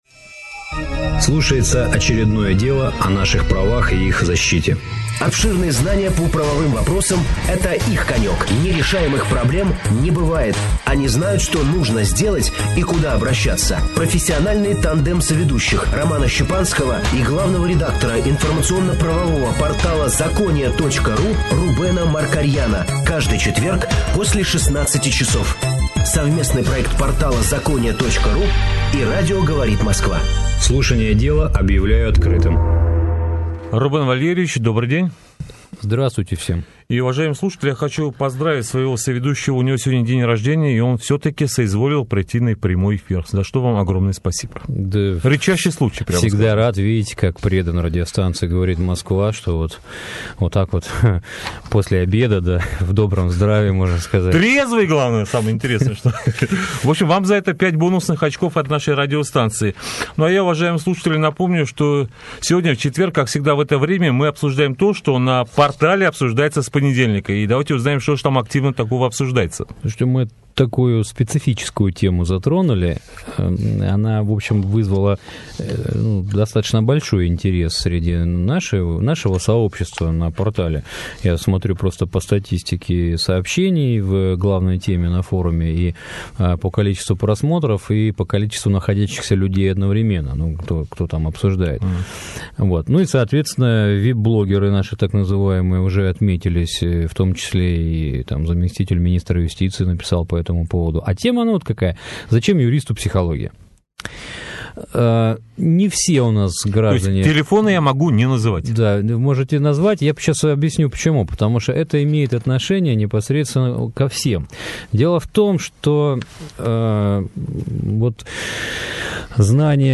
Совместный проект портала «ЗАКОНИЯ» и радио «Говорит Москва».